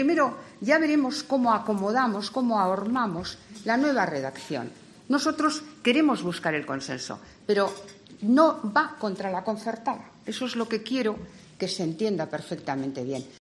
"Ahora mismo estamos ajustando redacciones alternativas de aquellos aspectos que nos parecieron que más vulneraban la igualdad de oportunidades y cuando lo tengamos nos pondremos a hablar con los grupos parlamentarios, con las comunidades autónomas y con otros agentes sociales", ha detallado Celaá en el desayuno informativo organizado por Europa Press este martes 2 de octubre en el Hotel Villa Magna de Madrid.